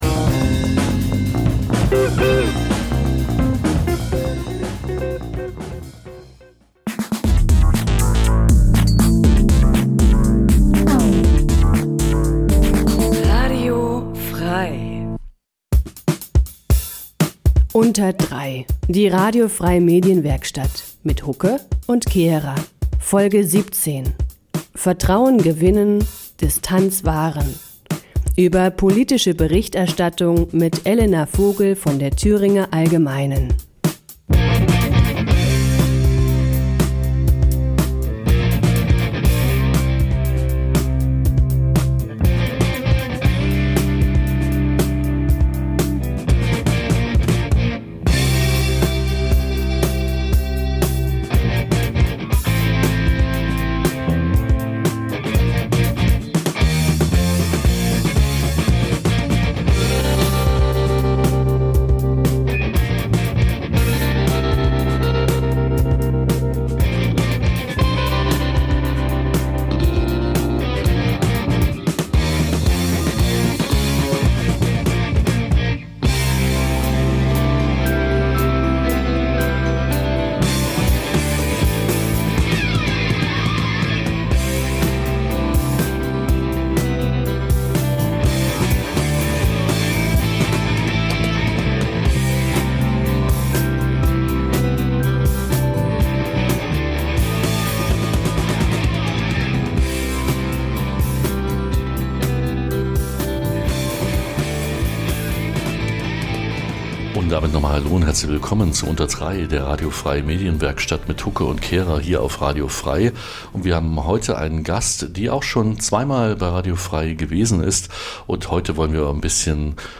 In �Unter Drei� widmen wir uns einmal im Monat dem Th�ringer Journalismus. In die einst�ndigen, thematischen Sendungen laden wir stets einen Gast ein, der oder die aus seinem/ihrem journalistischen Alltag im Freistaat erz�hlt. Dabei wollen wir nicht nur die Unterschiede der drei Gewerke Radio, Fernsehen, Zeitung/Online beleuchten, sondern auch einen Blick auf verschiedene Sender und Verlage werfen.